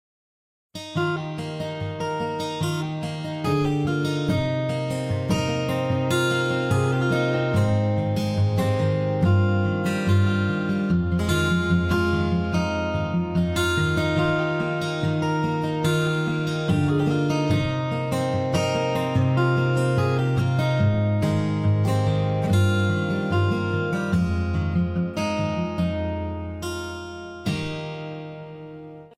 Virtual Instrument for Music production!